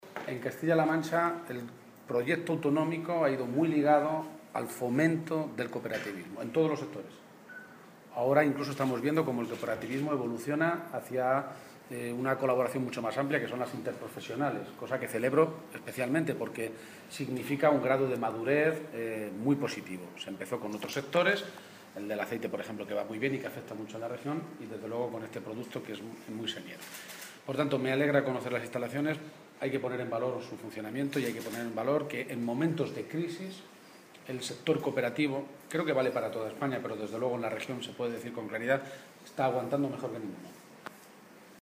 García-Page se pronunciaba de esta manera esta tarde, en Tomelloso, en unas declaraciones a los medios de comunicación en las que hacía referencia al hecho de que esta campaña de vendimia haya comenzado con los precios más bajos de la uva de los últimos 25 años.